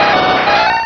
Cri de Galopa dans Pokémon Rubis et Saphir.